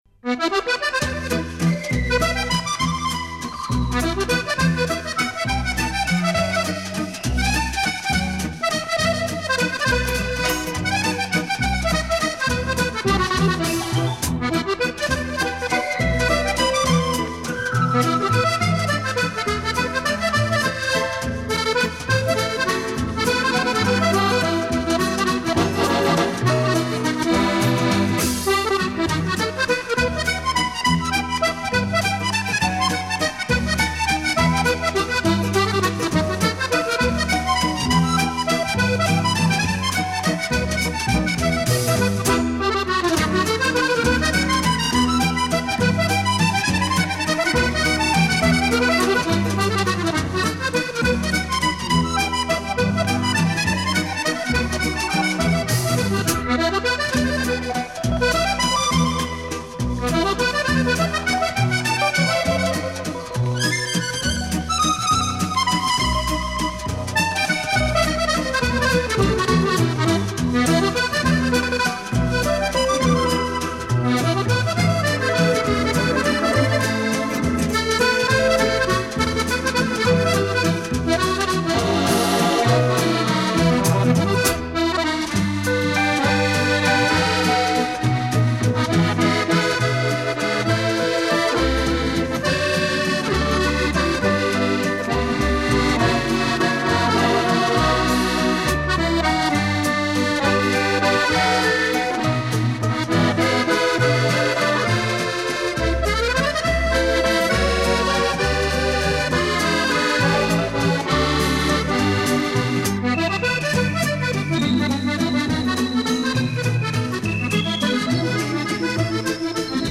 Французский вальс-мюзетт  Indifference